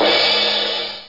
Crash Cymbal Sound Effect
Download a high-quality crash cymbal sound effect.
crash-cymbal.mp3